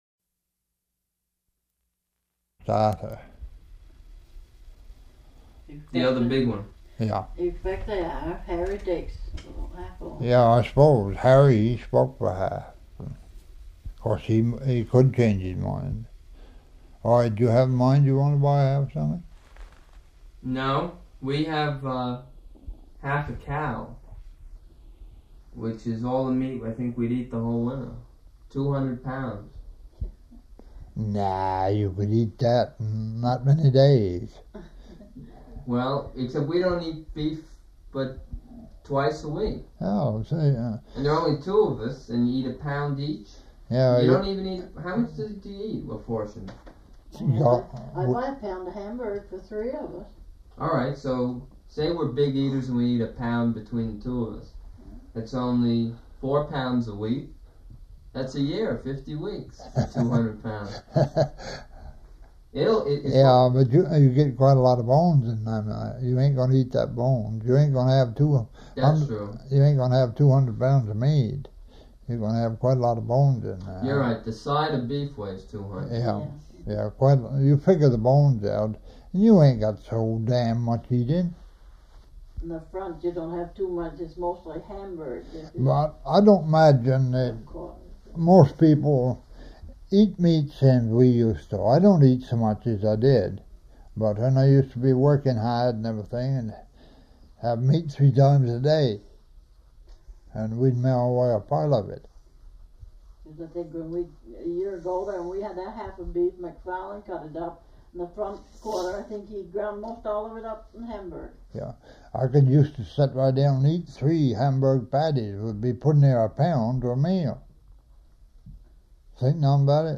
Interview: Meat consumption, foodways, maple syrup.
Format 1 sound tape reel (Scotch 3M 208 polyester) : analog ; 7 1/2 ips, full track, mono.